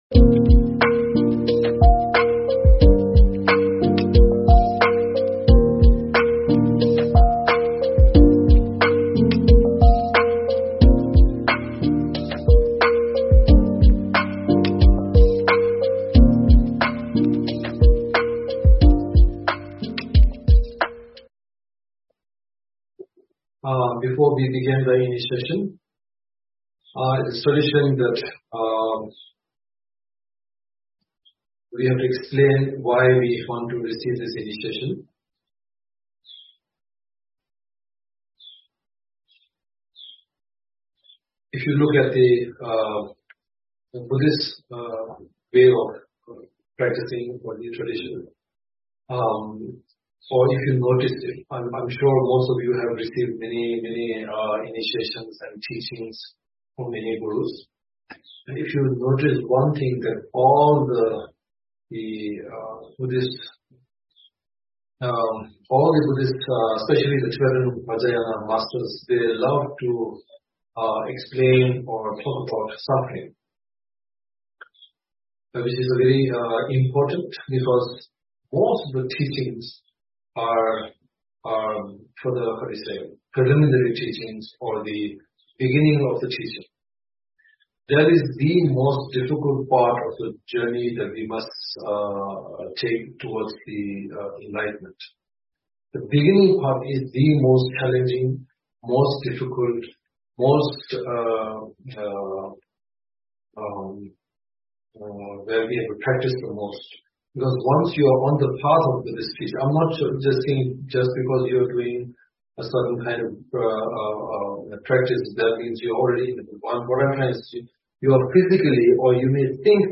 Topic: On Self-Understanding and the Importance of Basic Questioning Author: H.H. the 43rd Sakya Trizin Venue: Online Video and Audio Source: Sakya Friends Youtube